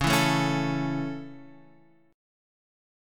C#m#5 chord